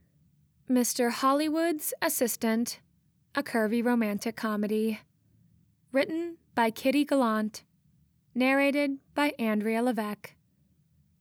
Still though - now that it’s mastered and it passes ACX standards, I hear a but of a weird lingering noise in the background.
It’s not the classic humming im used to hearing in my unedited files.
It’s nearly 100% certain that rumble is one of your fans in the background.
Also, we note I can hear you moving and gasping.
I really can’t see anything aside from my computer capable of making that kind of white noise.